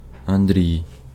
PronunciationUkrainian: [ɐnˈd⁽ʲ⁾r⁽ʲ⁾ij]